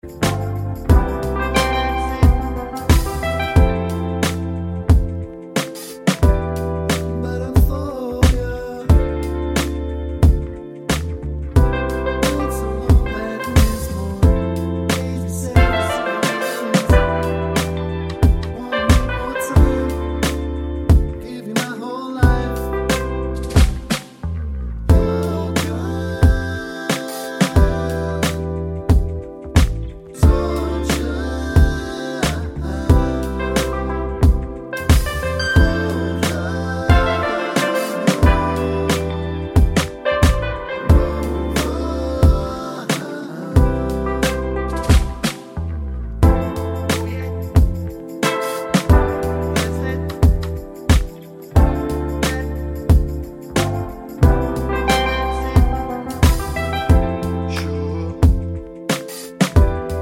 Clean Backing Vocals Pop (2020s) 3:17 Buy £1.50